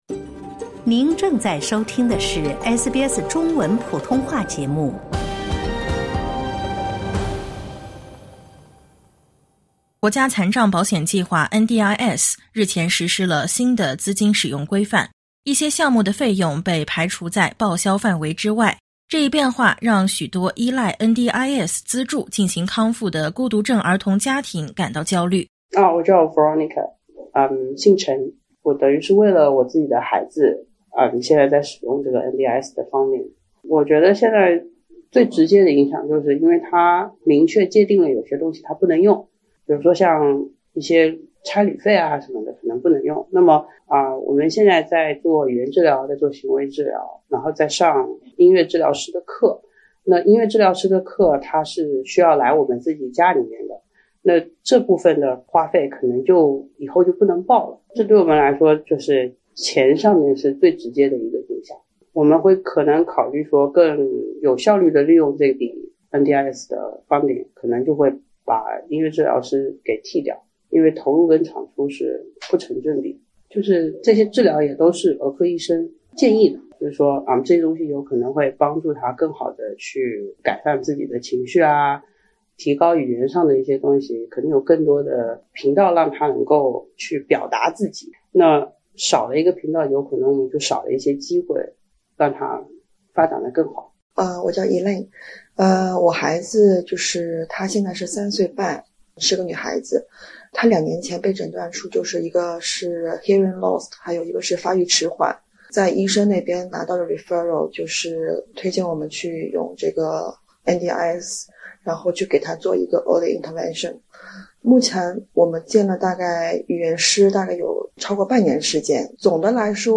国家残障保险计划（NDIS）10月3日起实施新的资金使用规范，明确规定包括日常生活杂费、旅游度假、代替疗法、性服务等在内的多项费用将不再被报销。业内人士对资金使用新规的出台持有怎样的态度？华人孤独症儿童家庭是否会因此受到影响？点击音频，收听综合报道。